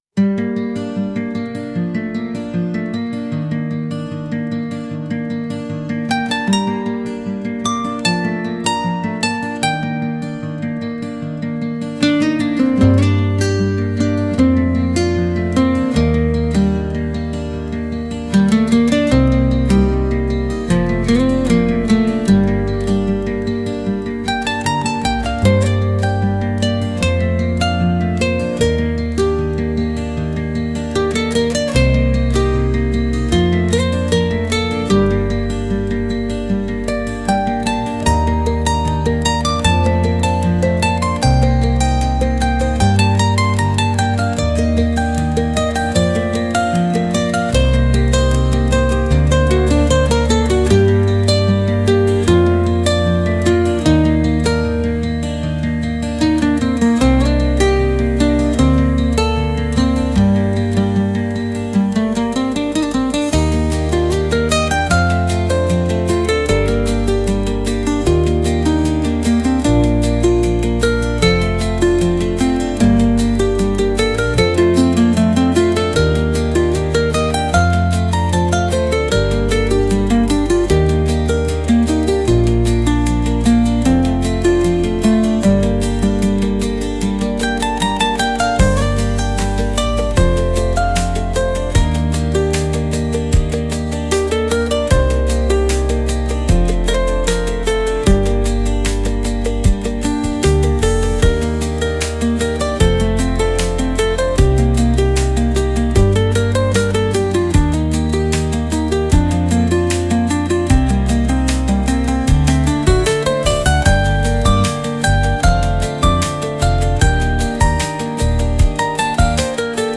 instrumental.mp3